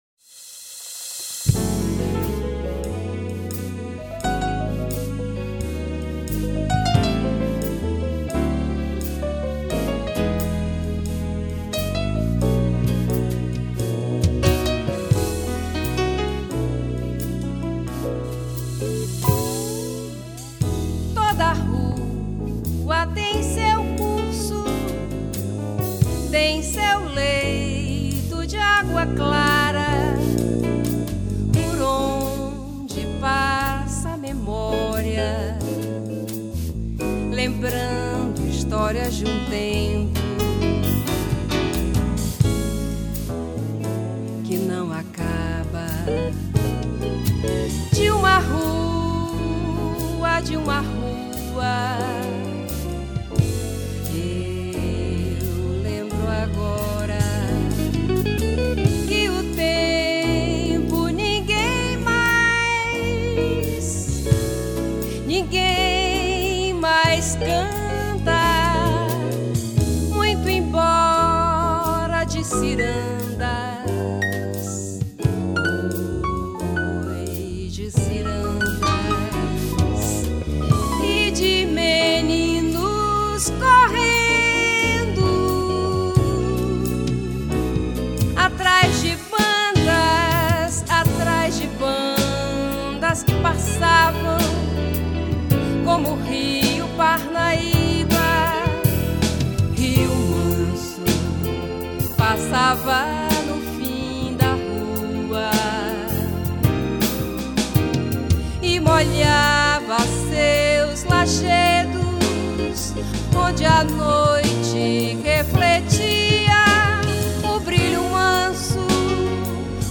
696   05:30:00   Faixa:     Jazz